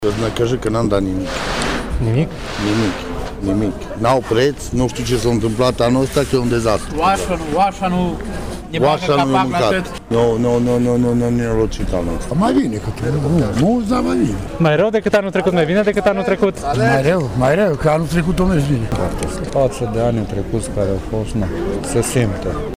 Vânzătorii spun că nu reușesc să vândă brazii pentru că hipermarketurile au acaparat piața :